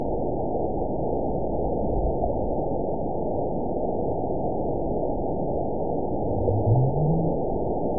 event 920406 date 03/23/24 time 23:14:17 GMT (1 year, 1 month ago) score 9.63 location TSS-AB02 detected by nrw target species NRW annotations +NRW Spectrogram: Frequency (kHz) vs. Time (s) audio not available .wav